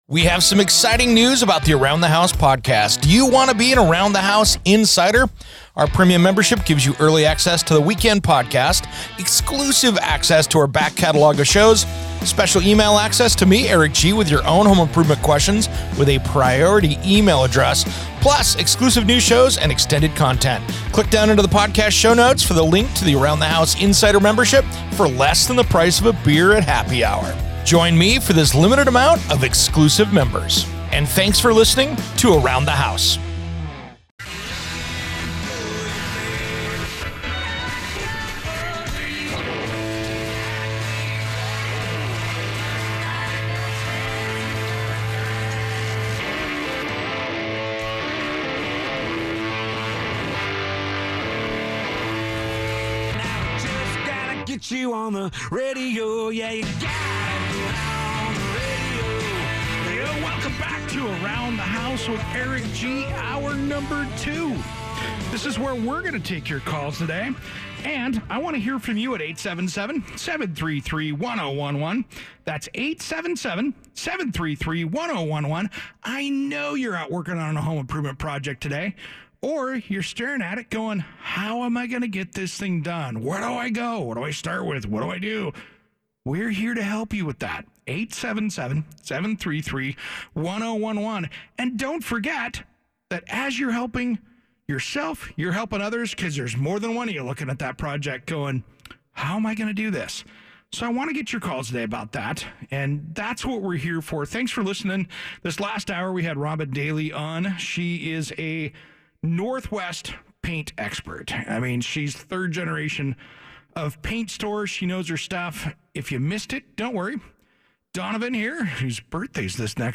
He also takes calls from listeners about their projects and around the house questions.